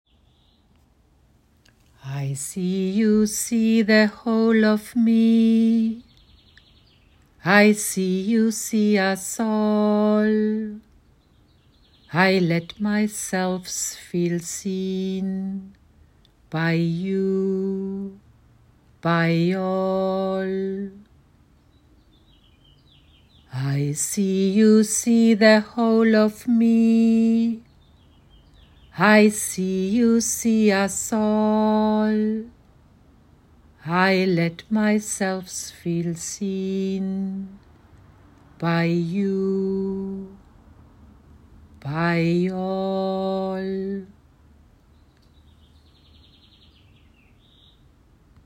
The chant that came to me, when I asked what it feels like to feel seen by the Spirit of a ficus tree bark.